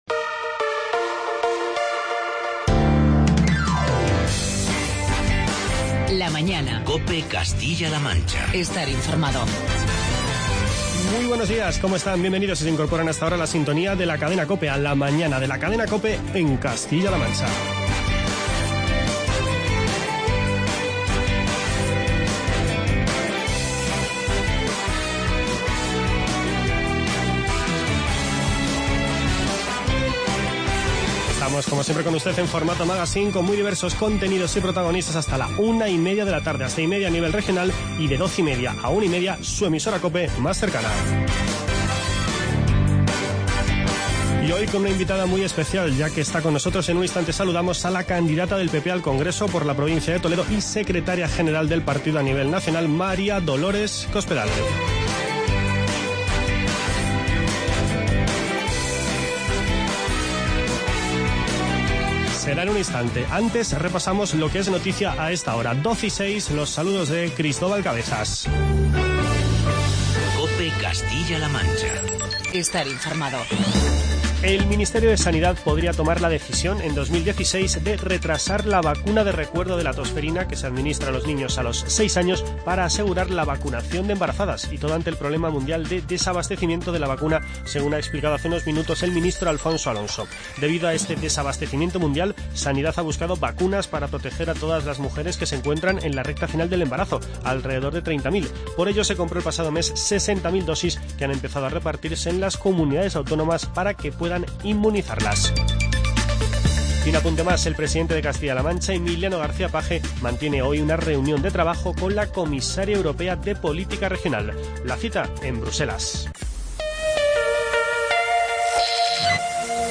Hoy visita los estudios de COPE Castilla-La Mancha la candidata del PP al Congreso por la provincia de Toledo y secretaria general del partido a nivel nacional, María Dolores Cospedal